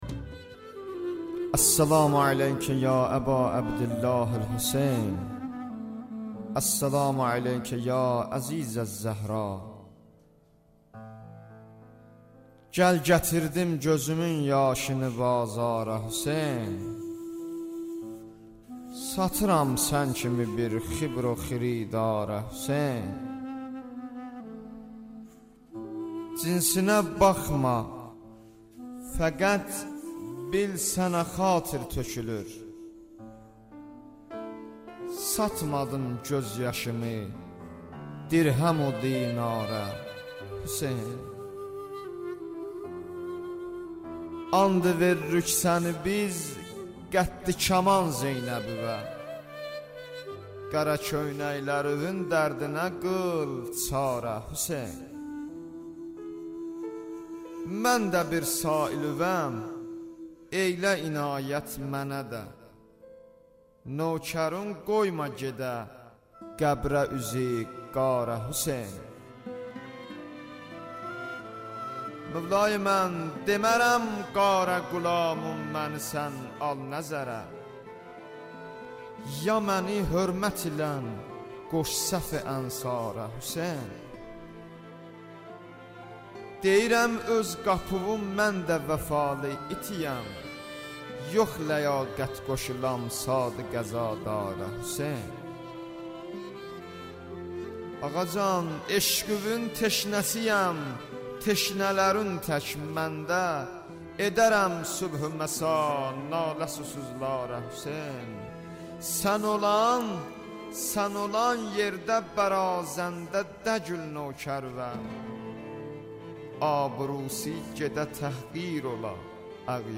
Məhərrəm Nəvası 1 (Audio)